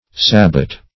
Sabot \Sa`bot"\ (s[.a]`b[=o]"), n. [F.]